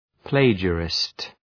Προφορά
{‘pleıdʒərıst}